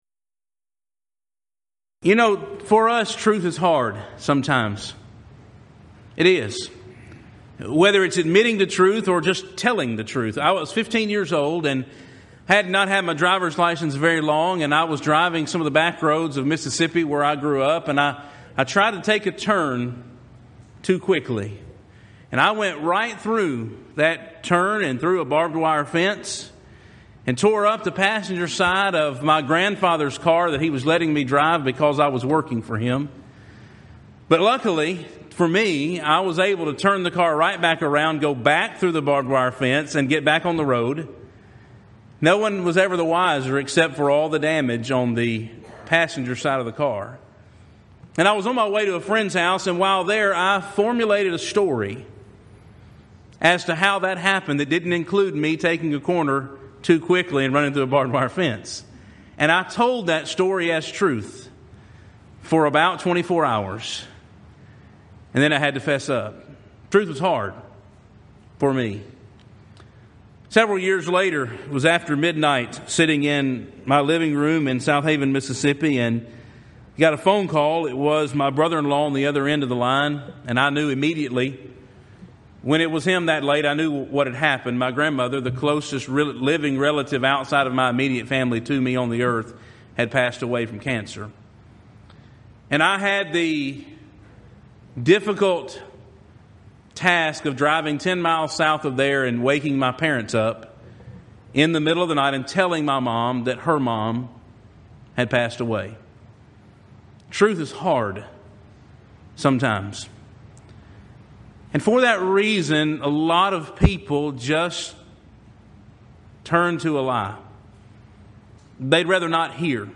Event: 2015 South Texas Lectures
lecture